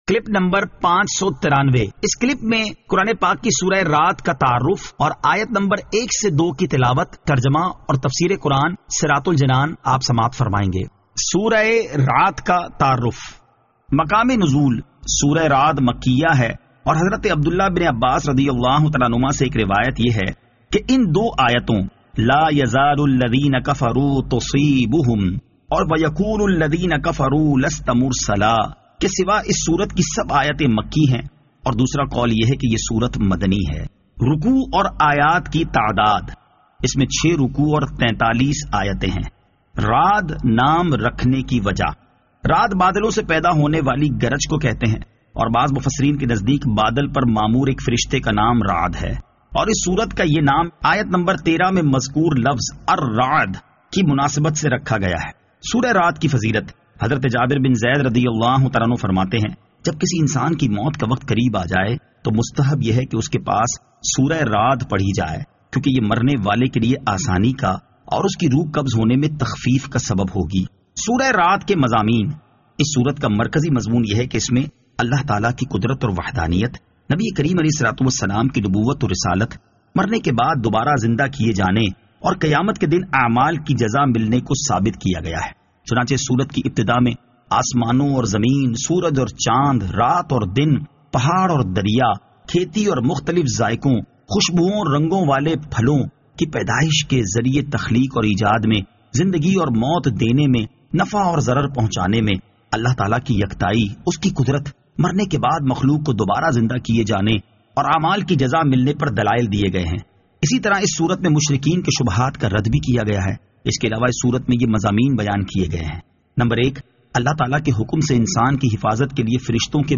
Surah Ar-Rad Ayat 01 To 02 Tilawat , Tarjama , Tafseer